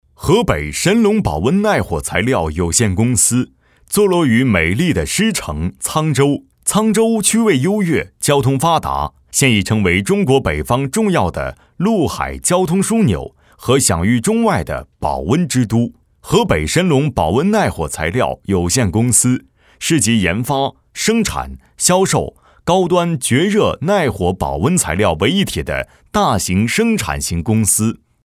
男27号